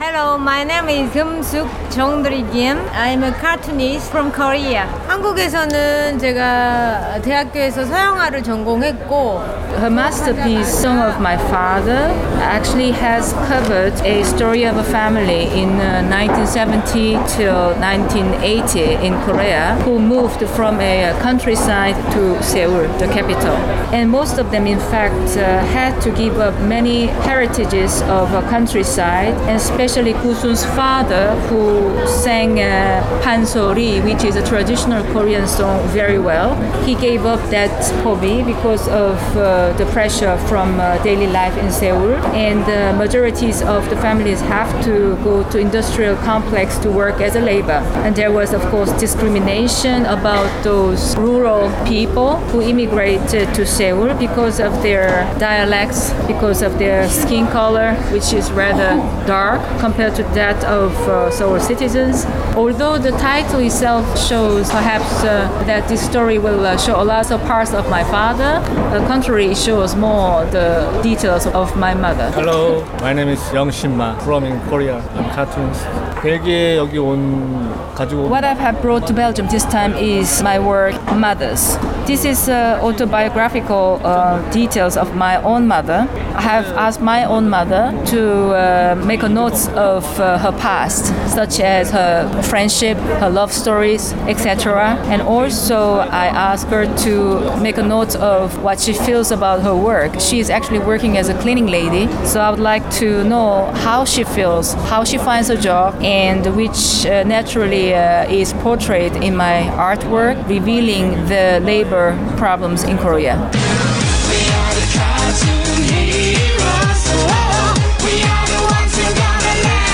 Another measure of its position was the many comic book artists and publishing houses from around the world who took part. We spoke with artists and publishers from Korea and Hong Kong as well as organizers of the Brussels Algiers Havana Triangle project and a purveyor of English language comics and graphic novels in Brussels.